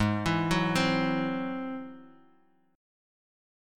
Listen to Ab+7 strummed